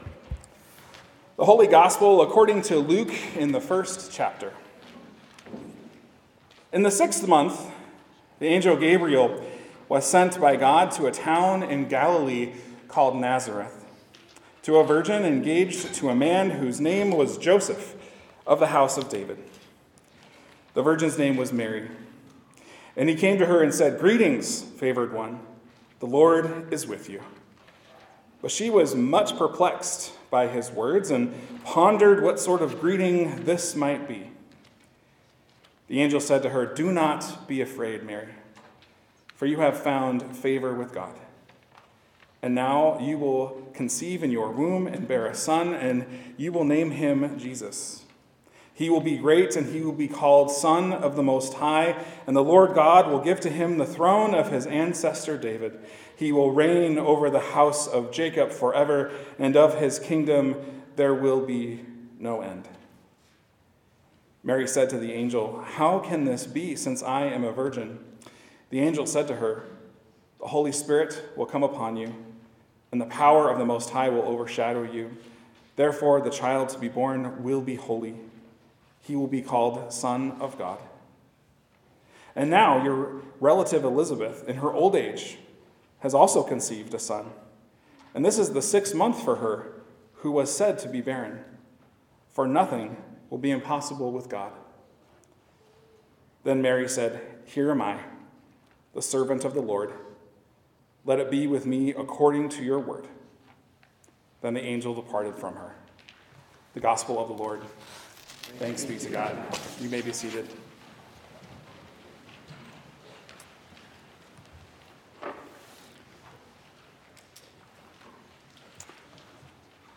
In this sermon, we are reminded of an everlasting truth: that we are God's beloved - precious, loved, and redeemed.